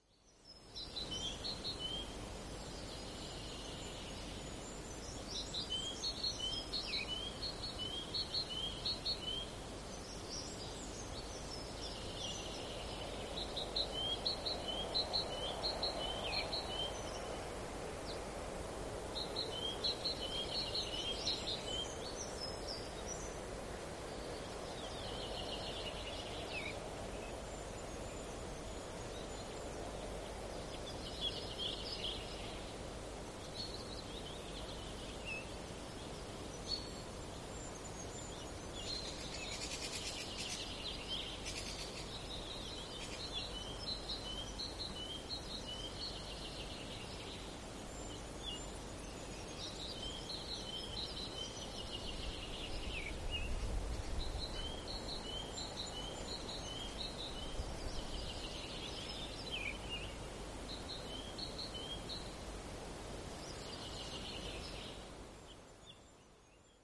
Tiaiset
Talitintin kolmitavuinen ti-ti-tyy (tai
titityi.mp3